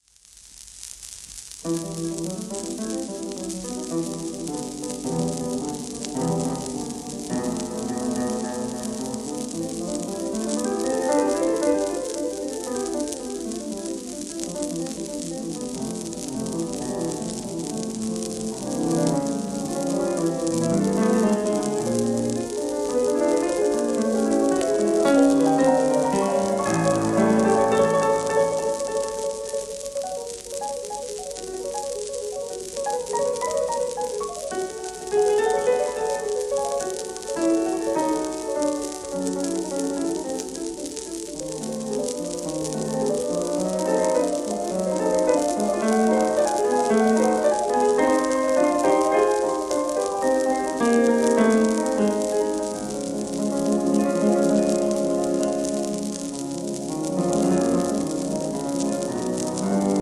シェルマン アートワークスのSPレコード